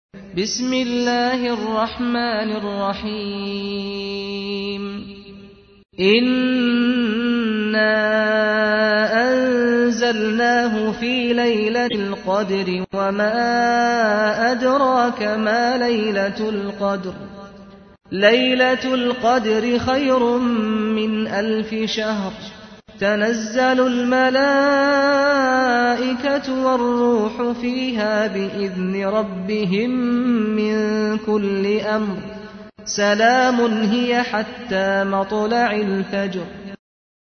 تحميل : 97. سورة القدر / القارئ سعد الغامدي / القرآن الكريم / موقع يا حسين